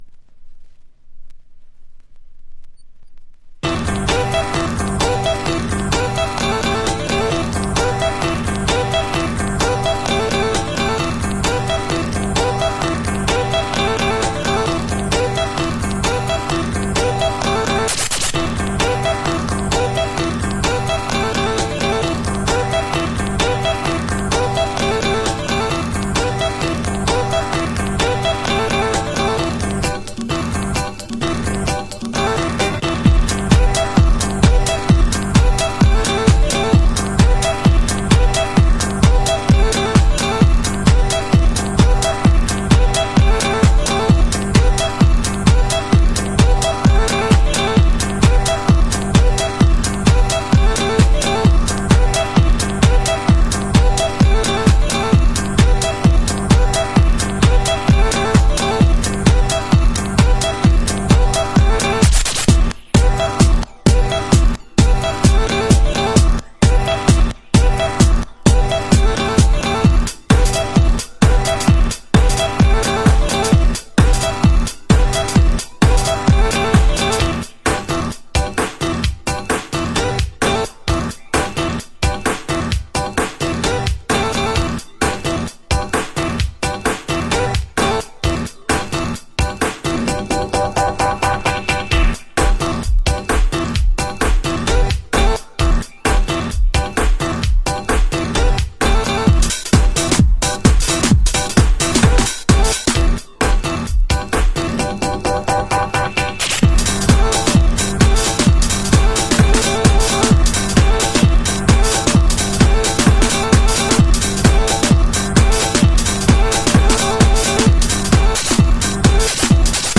House / Techno
センス溢れる音の抜き差しと随所にブチ込まれるスクラッチとTR909のビートが単純にカッチョいい。